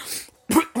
*Cough*